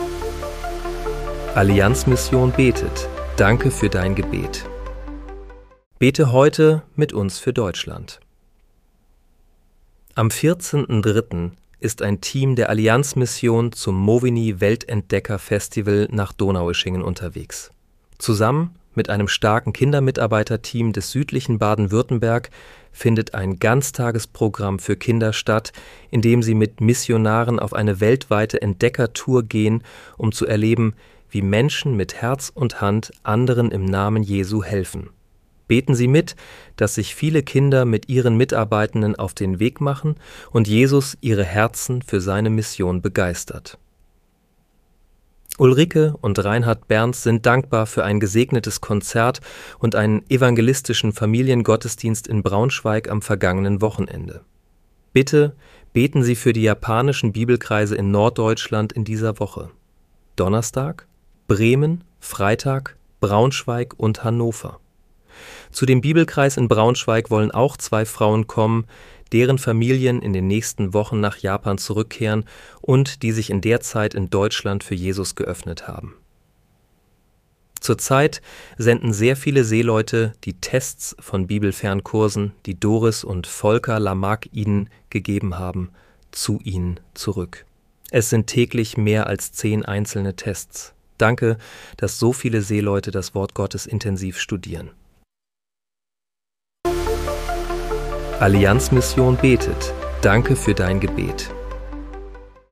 Bete am 12. März 2026 mit uns für Deutschland. (KI-generiert mit